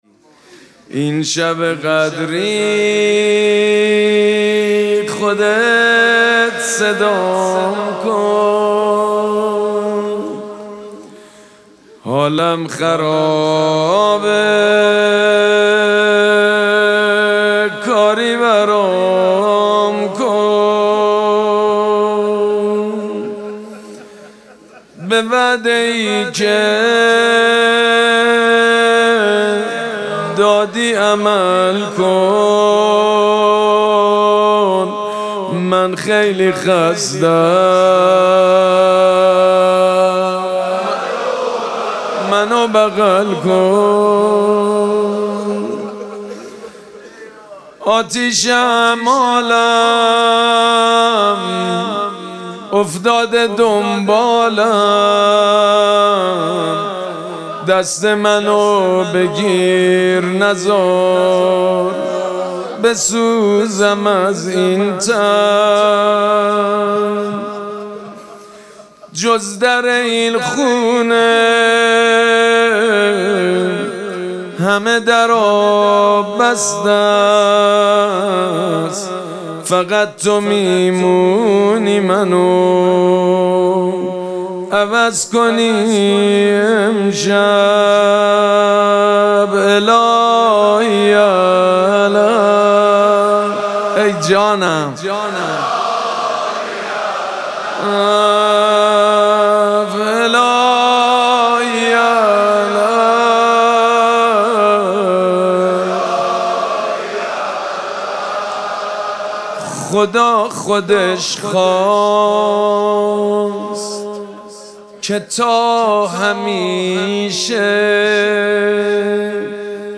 مراسم مناجات شب بیست و سوم ماه مبارک رمضان
حسینیه ریحانه الحسین سلام الله علیها
مناجات
حاج سید مجید بنی فاطمه